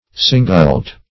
singult - definition of singult - synonyms, pronunciation, spelling from Free Dictionary
Search Result for " singult" : The Collaborative International Dictionary of English v.0.48: Singult \Sin"gult\, n.[L. singultus.]